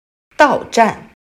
到站/Dào zhàn/Llegada